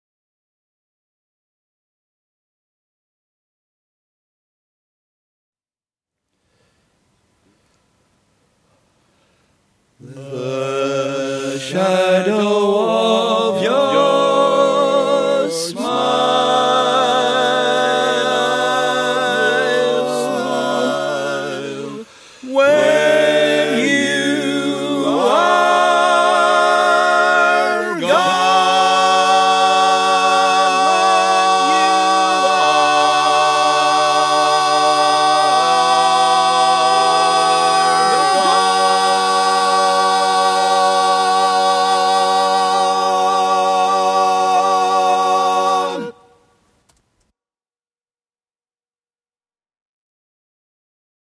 Rainy day - playing with a USB microphone: